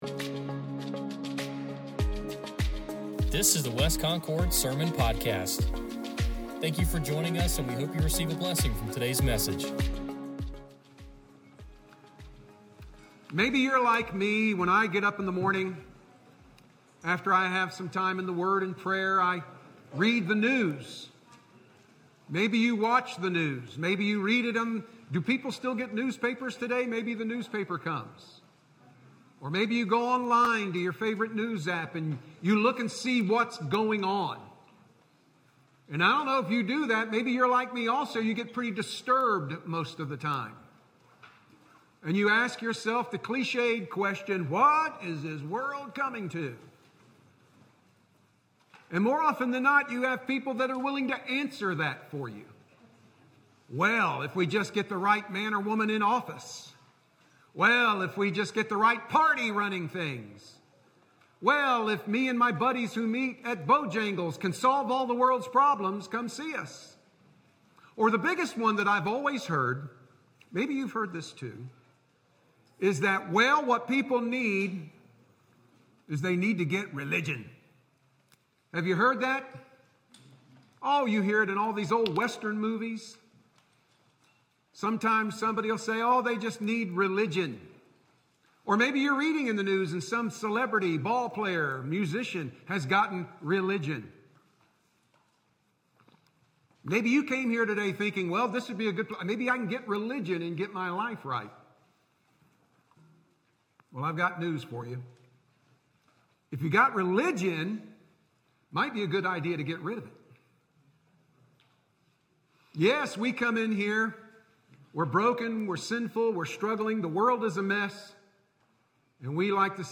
Sermon Podcast | West Concord Baptist Church